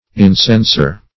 Incenser \In*cen"ser\, n. One who instigates or incites.